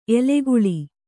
♪ eleguḷi